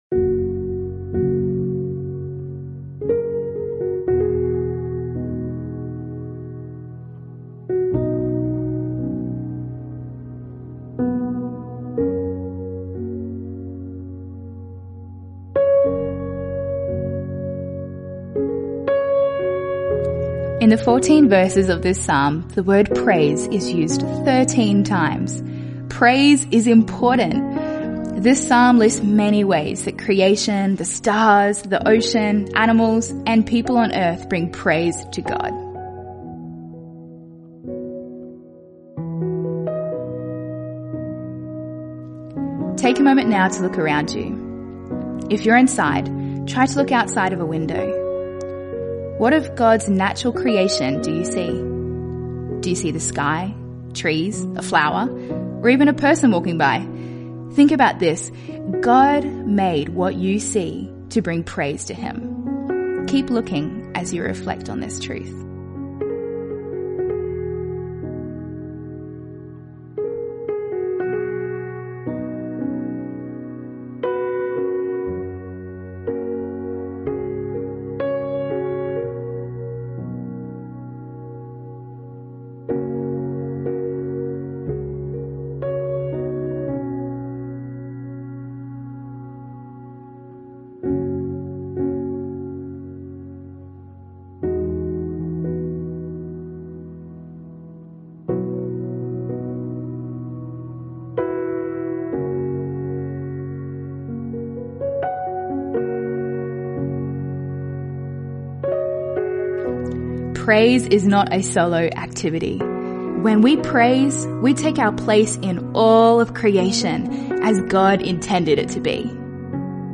After you have completed today’s reading from the Bible, we encourage you to set aside a moment to listen along to the audio guide provided as we pray and allow God to speak to us through His word.